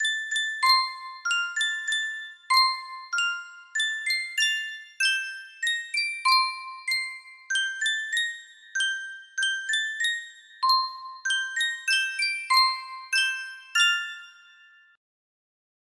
Swisstone Mini Music Box - Brahms' Lullaby music box melody
Full range 60